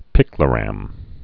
(pĭklə-răm, pīklə-)